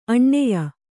♪ aṇṇeya